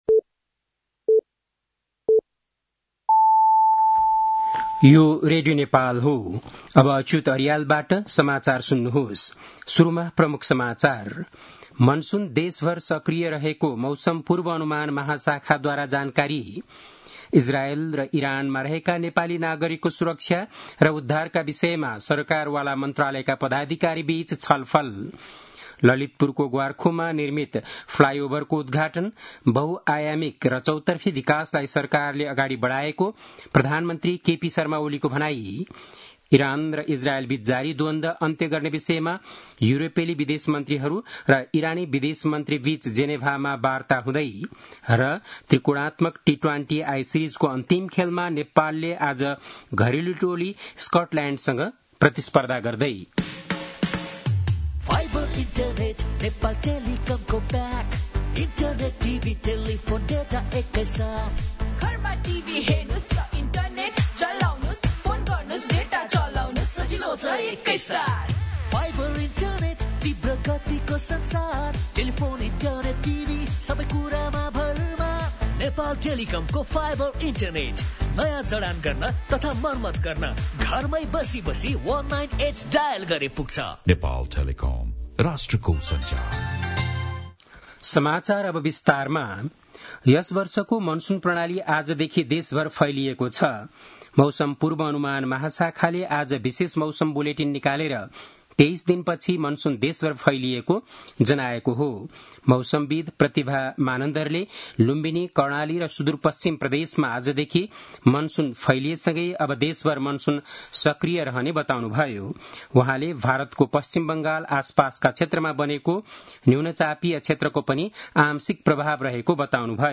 बेलुकी ७ बजेको नेपाली समाचार : ६ असार , २०८२